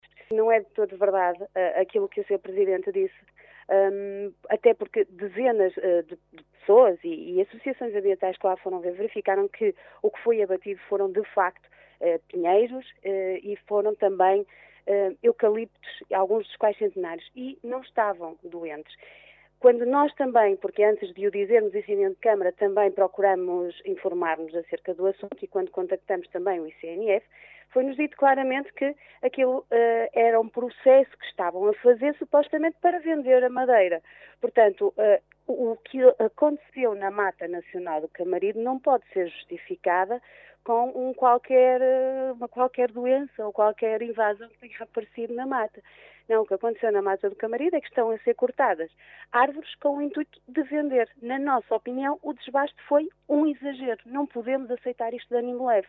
Os vereadores do PSD manifestaram ontem na Reunião de Câmara o seu total desagrado pelo desbaste de inúmeras árvores, nomeadamente “pinheiros e eucaliptos centenários”, que dizem estar a ser feito na Mata Nacional do Camarido, pelo Instituto de Conservação das Florestas e Natureza (ICNF).